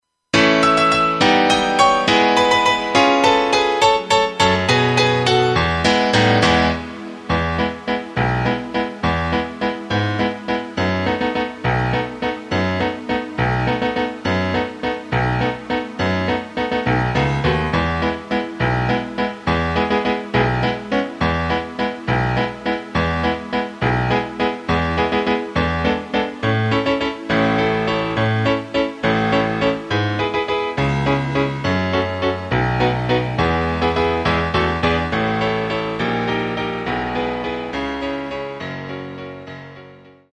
Takt:          3/4
Tempo:         207.00
Tonart:            E
HYMNE des Fußballvereins
Playback mp3 Mit Drums